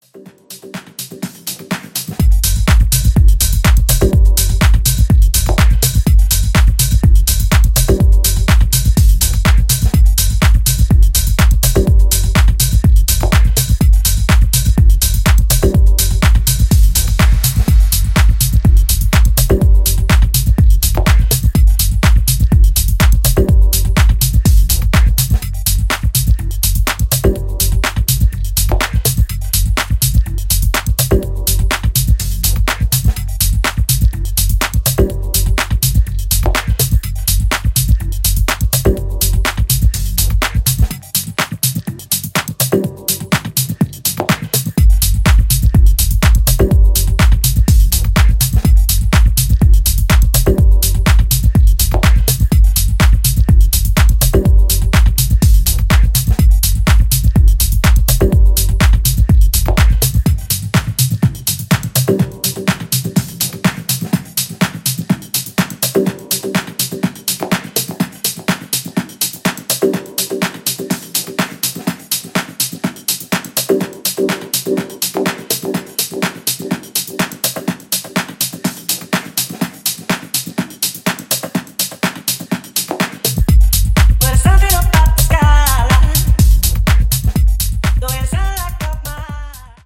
ジャンル(スタイル) MINIMAL / TECH HOUSE